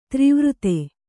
♪ trivute